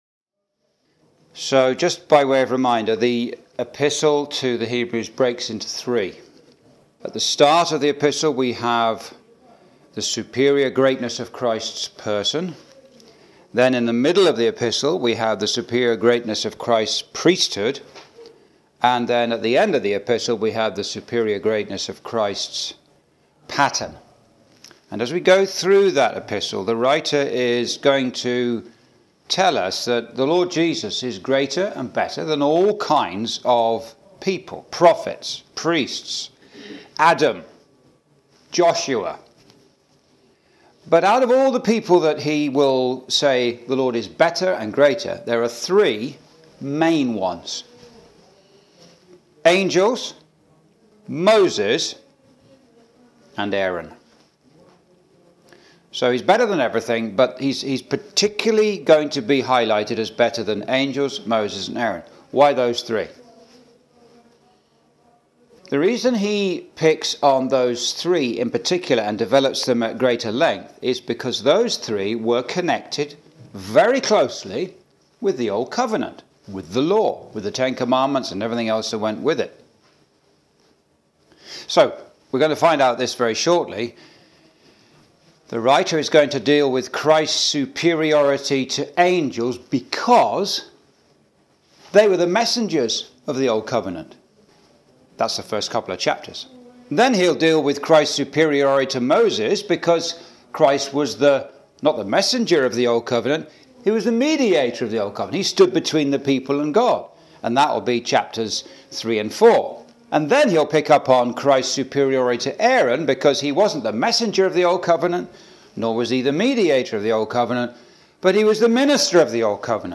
(Message preached in Chalfont St Peter Gospel Hall, 2024)
Verse by Verse Exposition